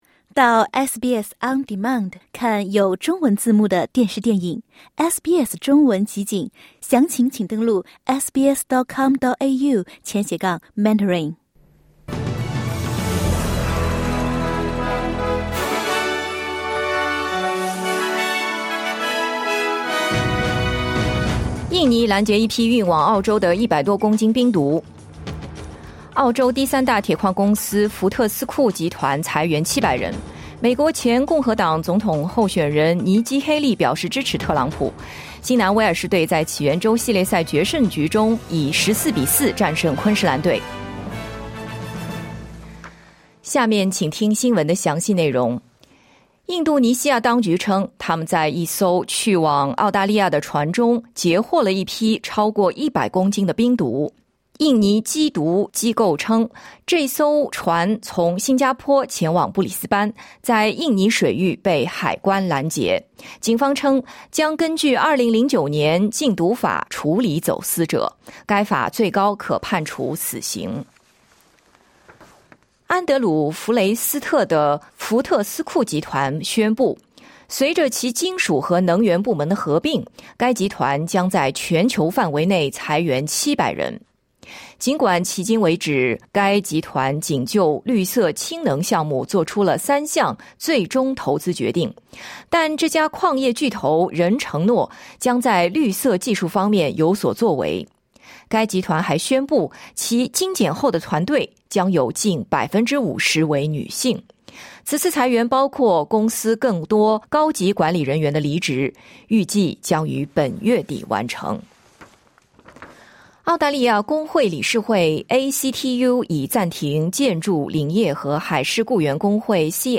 SBS早新闻 (2024年7月18日)